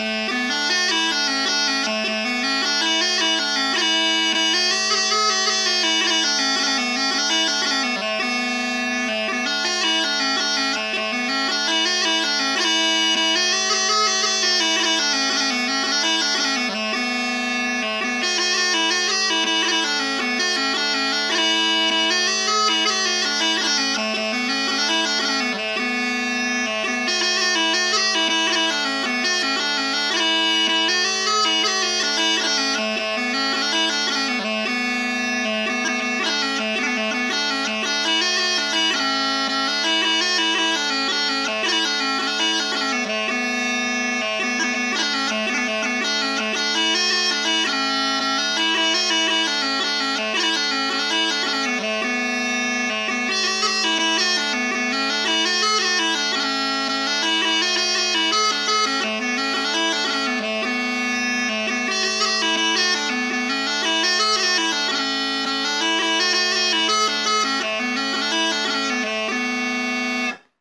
Another one with a Spanish feel.